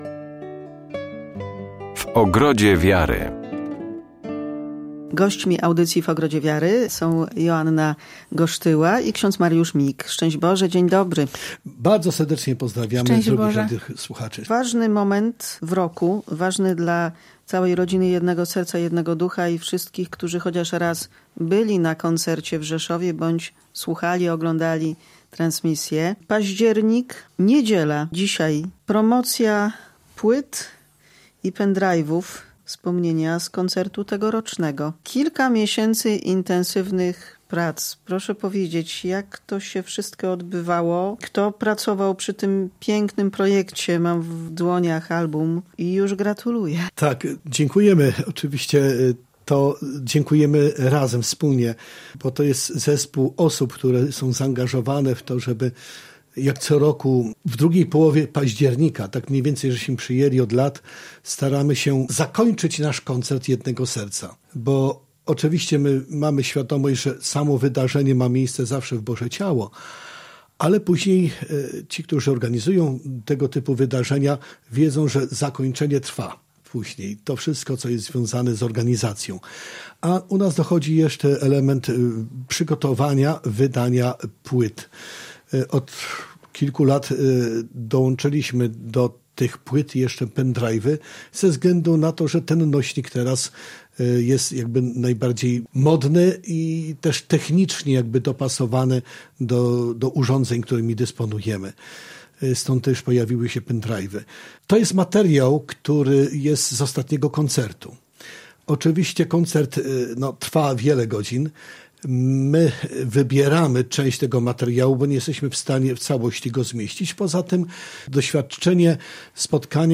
Więcej na temat spotkania w rozmowie z gośćmi magazynu katolickiego